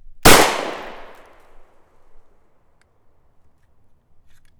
The sound if from a .40 Smith and Wesson being fired out of a subcompact Glock 27.
Gunshot.wav